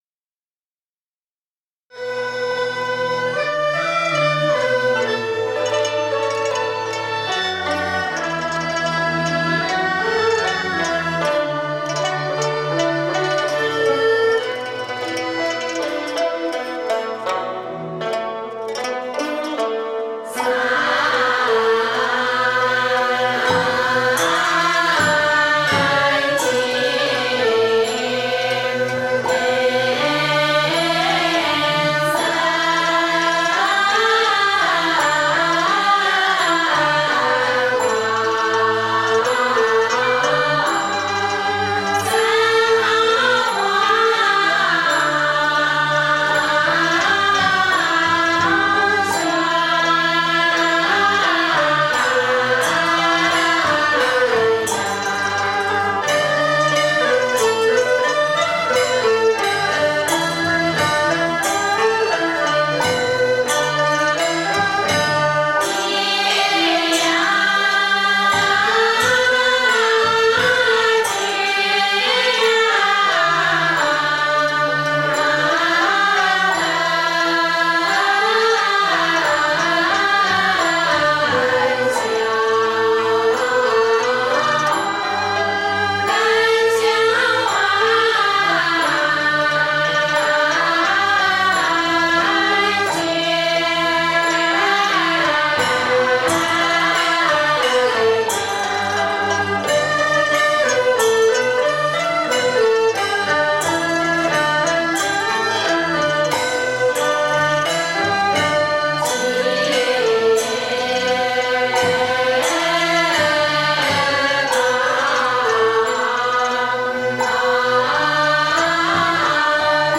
中国道教音乐-茅山乾元观道乐-单吊挂（经韵） - 道音文化
乾元观道教音乐初传，以笙、箫、管、笛、三弦、琵琶等六大件器乐为主，后经逐步演变，增配了扬琴、古筝、二胡、中胡、低音大胡等，有些器种还配备双人或四人，同时增加了系列打击乐器，形成了器乐、打击乐和声乐三大部分。
乾元观坤道仙乐内容主要分“经韵”、“曲牌”两大类：“经韵”是在斋醮活动中，以经文唱诵为主，并伴有法器敲打和乐器伴奏的韵腔；“曲牌”则是纯粹的乐器演奏，亦可添加词牌，多为寄情山水、净化人心或陶冶情操、修心养性等用。其韵腔以老道长心传口授为主，在全真十方正韵的基础上，蕴涵了江南丝竹和正一派的韵味，以及地方戏剧音乐元素，整体感觉细腻柔和、温婉清雅。